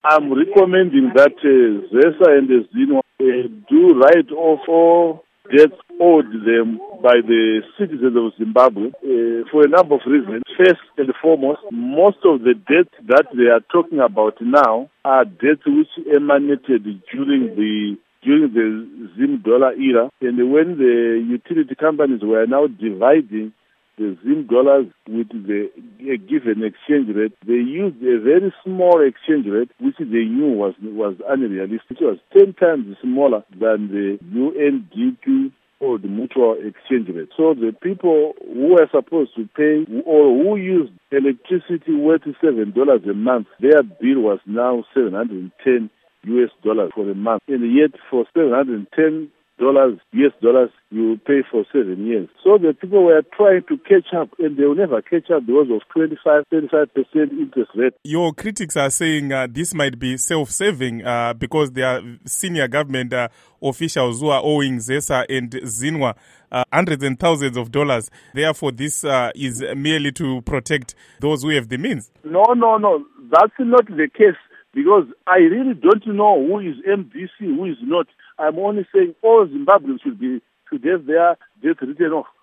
Interview With Ignatius Chombo